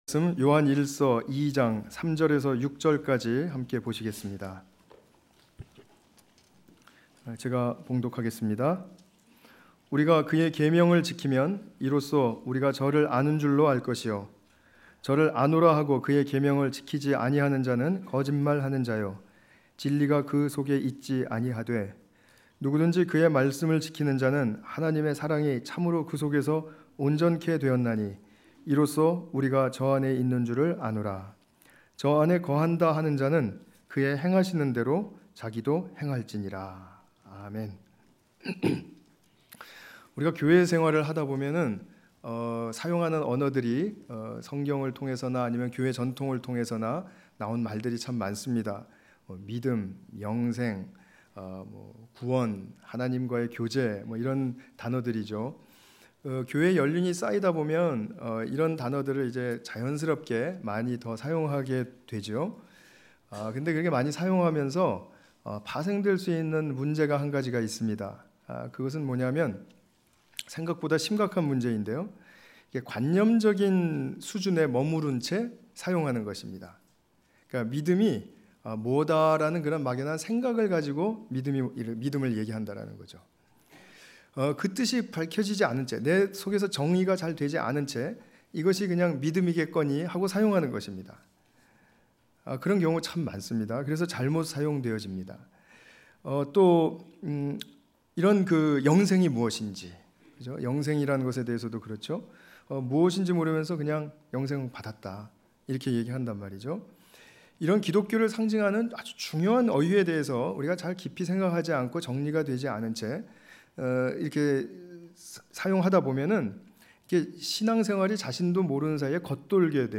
요한일서 2장 3-6절 관련 Tagged with 주일예배